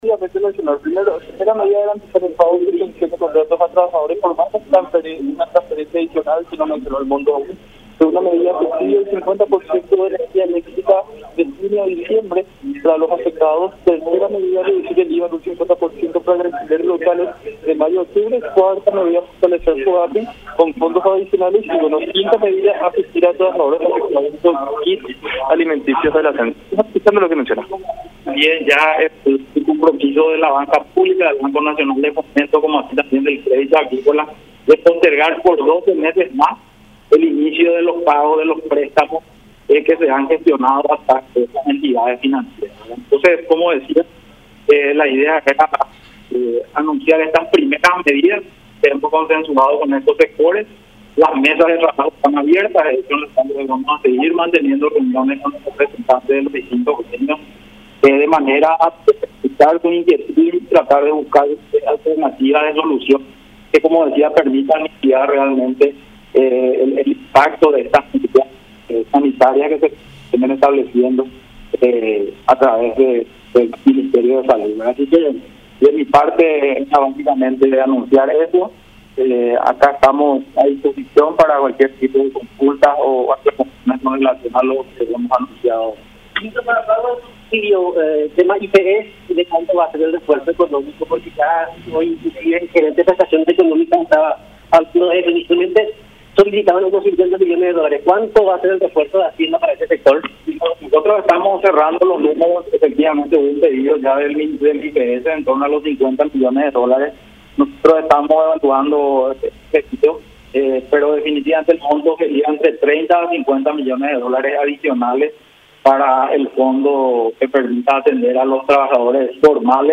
CONFERENCIA.mp3